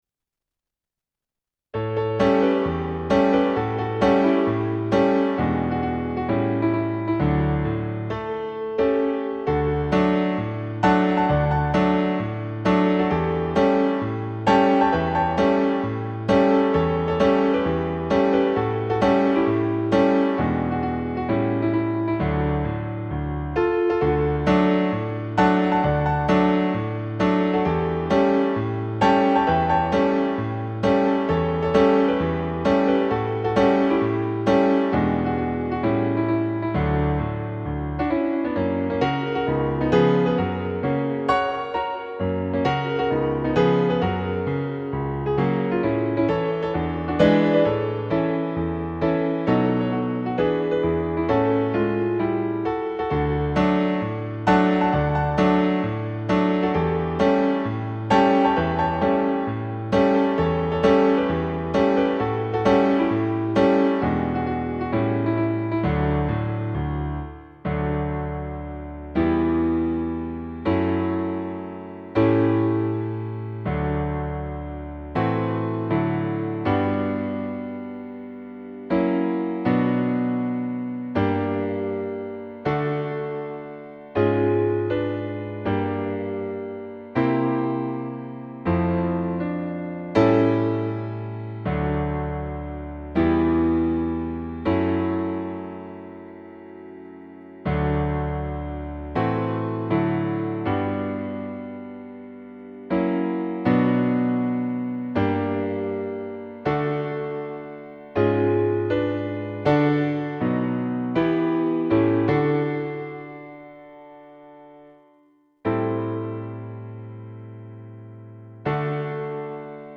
Festive-Finale-Backing.mp3